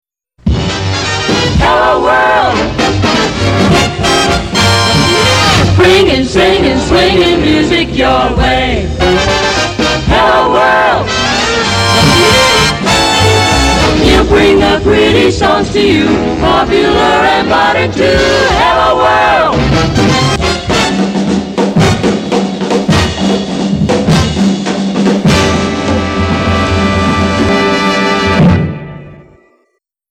JINGLES AND MORE AUDIO